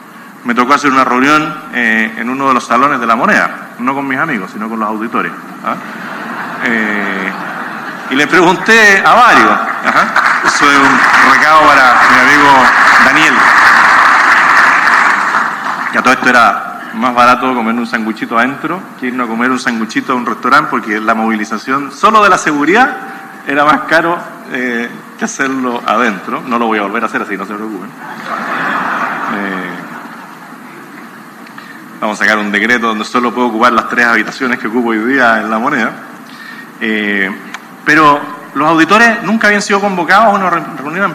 broma-de-kast-por-almuerzo-en-la-moneda.mp3